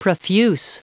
Prononciation du mot profuse en anglais (fichier audio)
Prononciation du mot : profuse